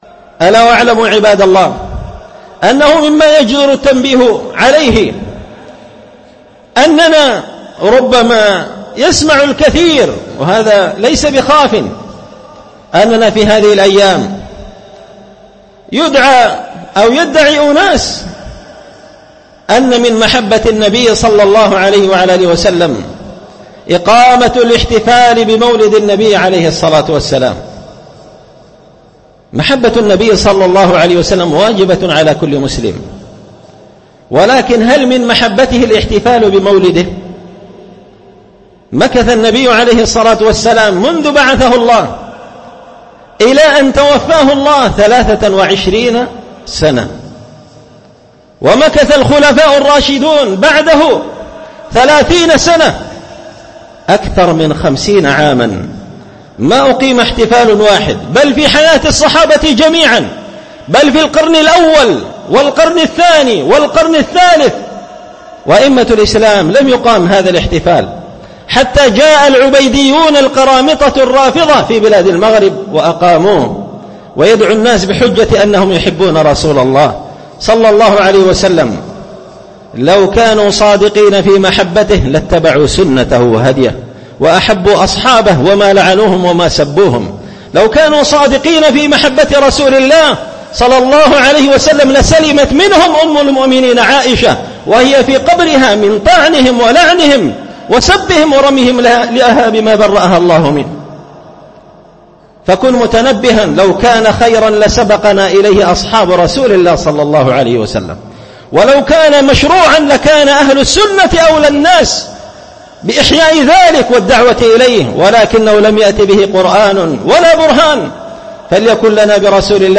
مقتطف من خطبة جمعة بعنوان 11 ربيع الأول_1444هـ
دار الحديث بمسجد الفرقان ـ قشن ـ المهرة ـ اليمن